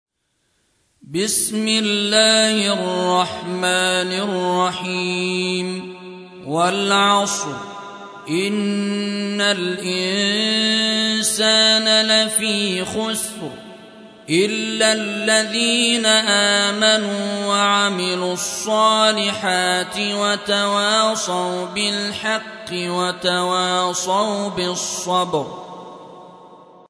103. سورة العصر / القارئ